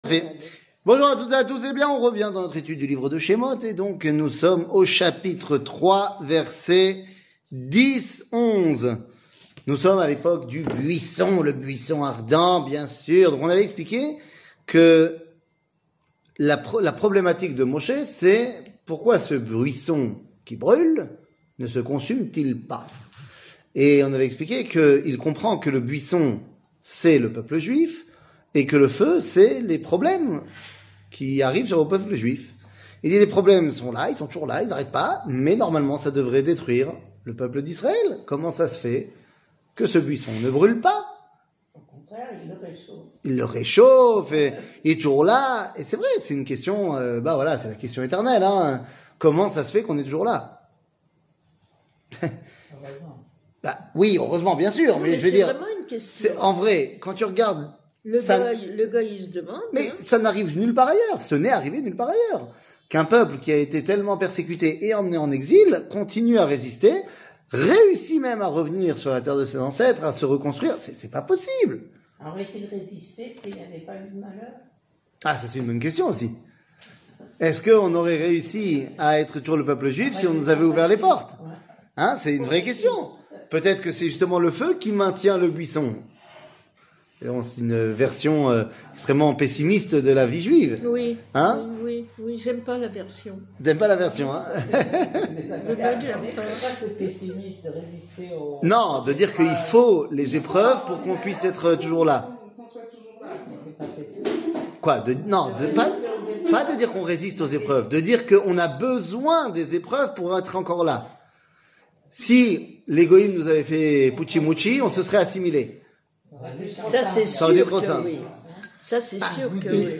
Livre de Chemot, 04, chapitre 3, verset 10 00:44:26 Livre de Chemot, 04, chapitre 3, verset 10 שיעור מ 09 נובמבר 2023 44MIN הורדה בקובץ אודיו MP3 (40.68 Mo) הורדה בקובץ וידאו MP4 (70.95 Mo) TAGS : שיעורים קצרים